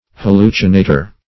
Meaning of hallucinator. hallucinator synonyms, pronunciation, spelling and more from Free Dictionary.